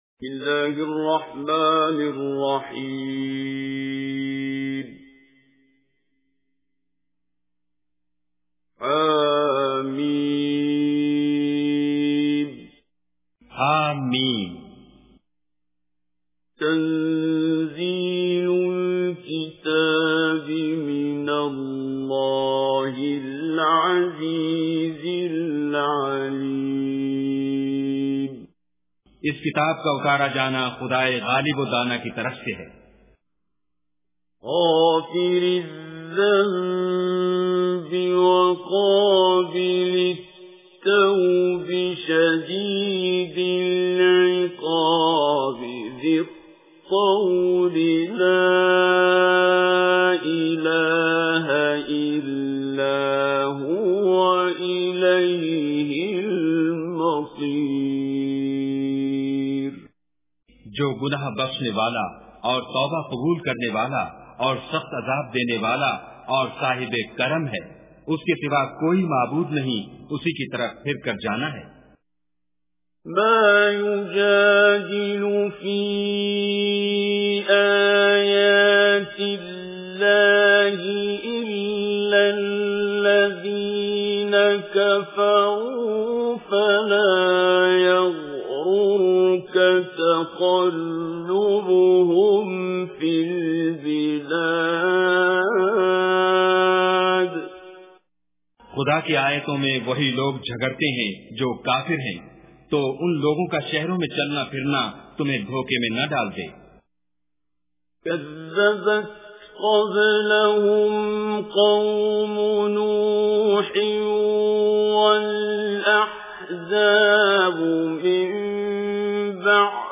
Surah Ghafir Recitation with Urdu Translation
Surah Ghafir or Surah Momin is 40th chapter of Holy Quran. Listen online mp3 recitation in Arabic of Surah Momin or Surah Ghafir in the voice of Qari Abdul Basit.